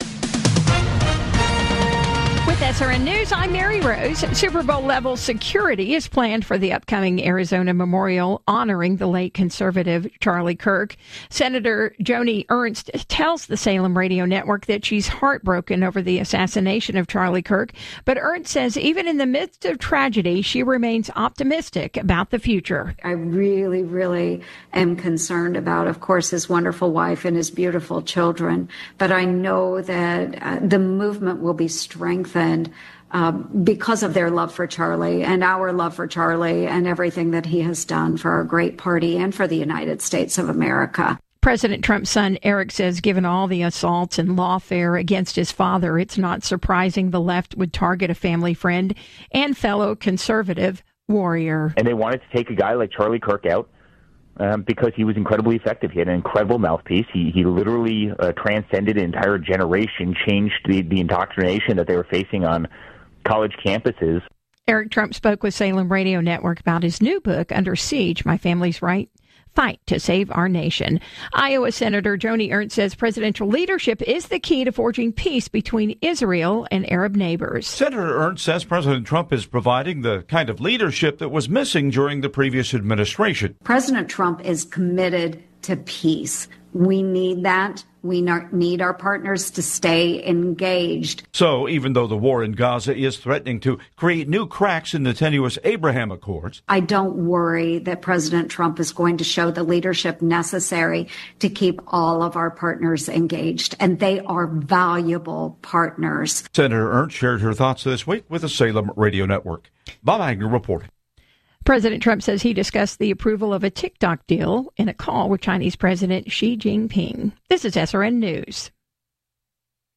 News stories as heard on SRN Radio News.